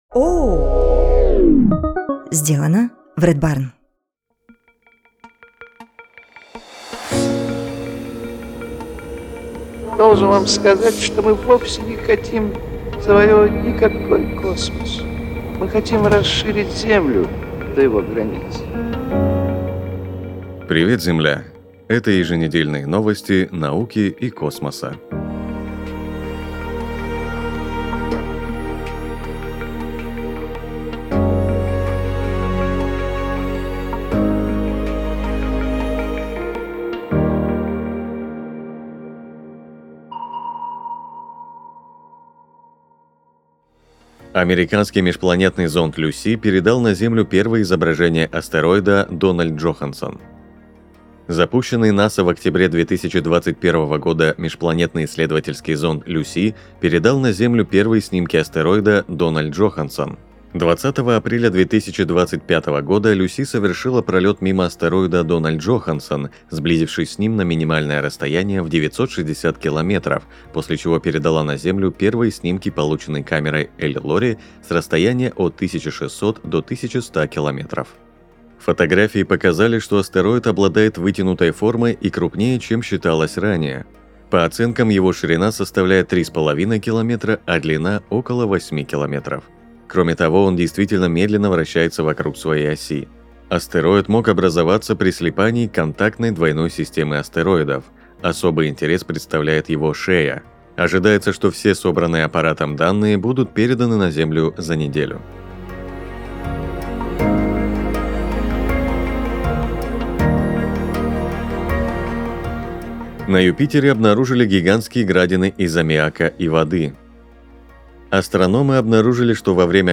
Ведет выпуск